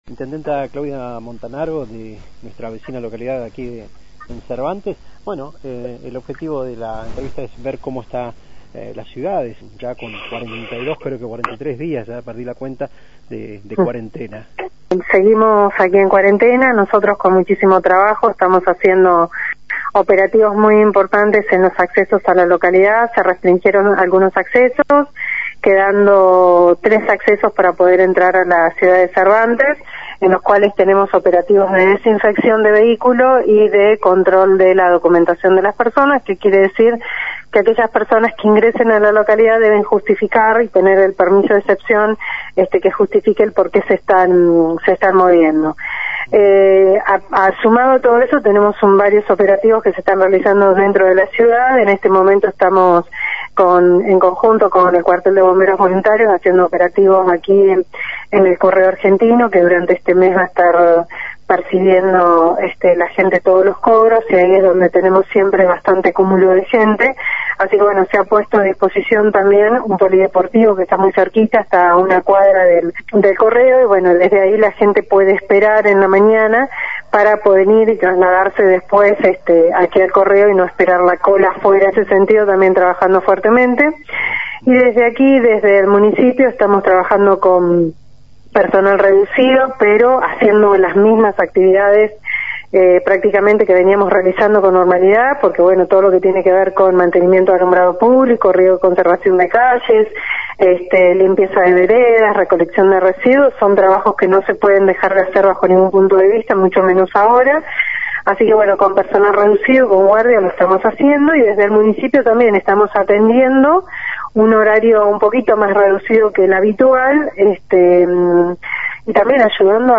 Claudia Montanaro, Intendenta de la ciudad de Cervantes